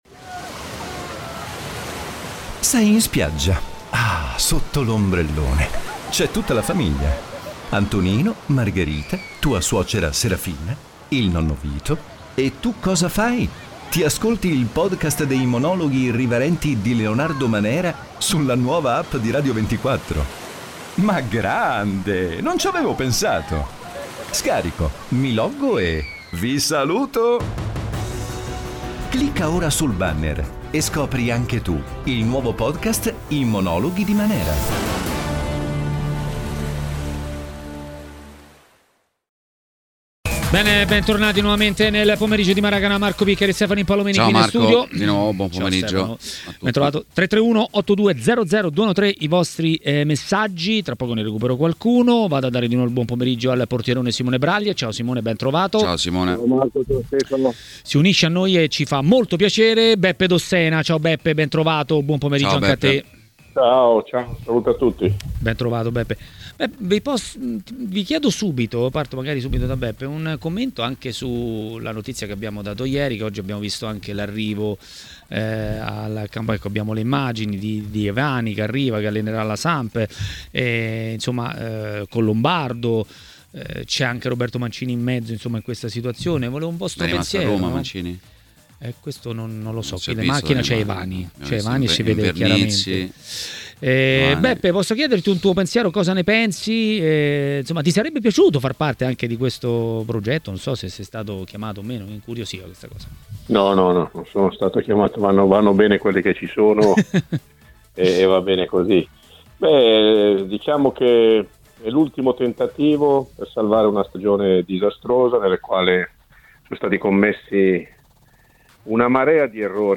L'ex calciatore Beppe Dossena a Maracanà, nel pomeriggio di TMW Radio, ha parlato di Sampdoria e non solo.